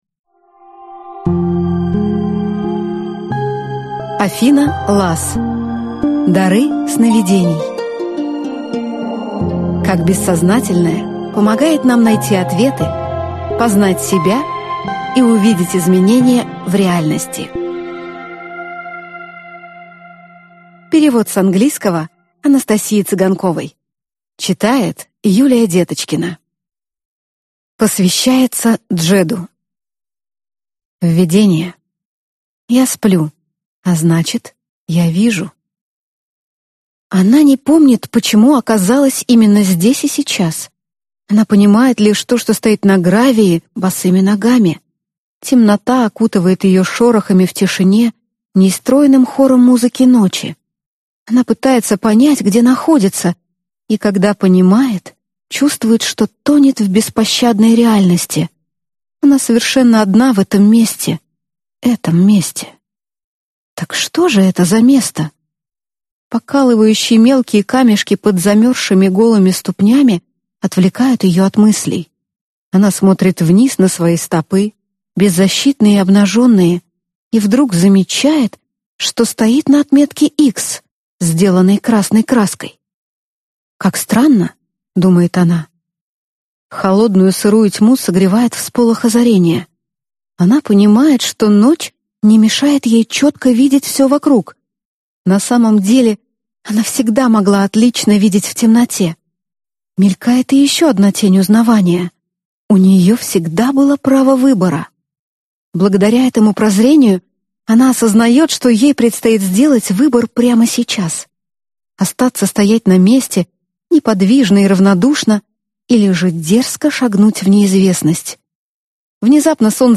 Аудиокнига Дары сновидений. Как бессознательное помогает нам найти ответы, познать себя и увидеть изменения в реальности | Библиотека аудиокниг